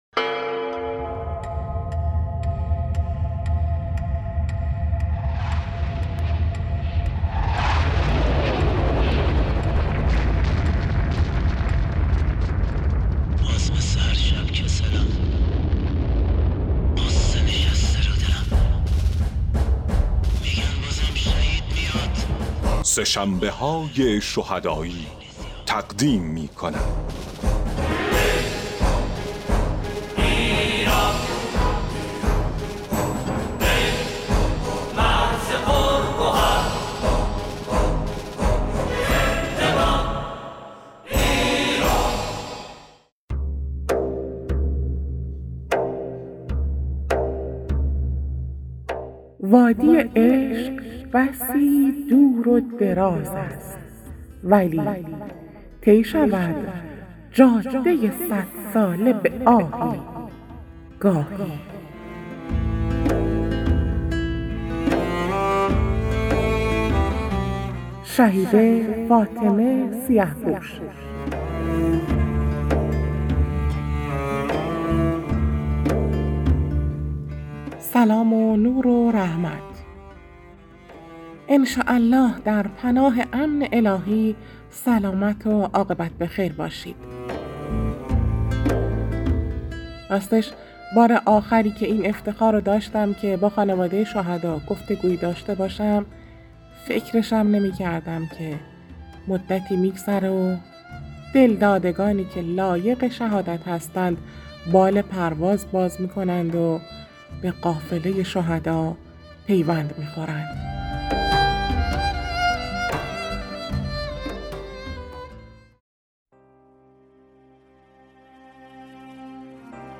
گفت‌وگوی صوتی